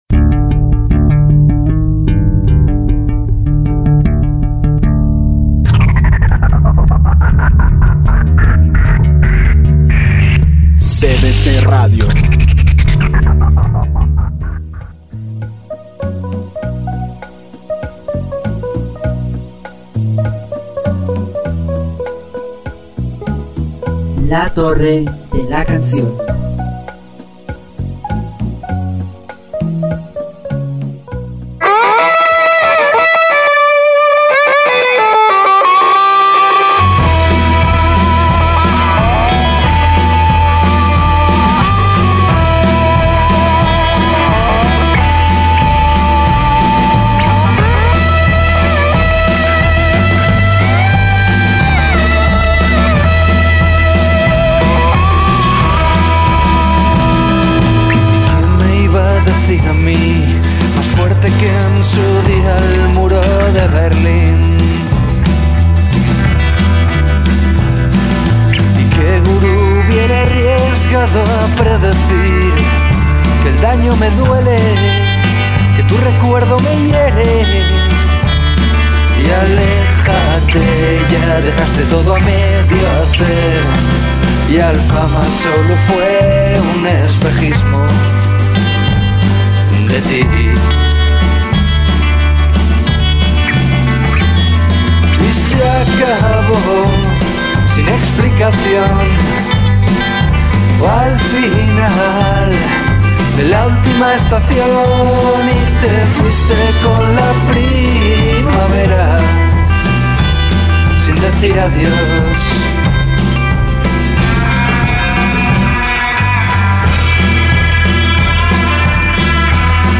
Como buen hijo prodigo, Cocktail Music for Robots vuelve a Cortesía de la Casa con un baúl musical cargado con sus canciones favoritas de noise. En este programa habrá desde pop noise y no wave, hasta noise experimental.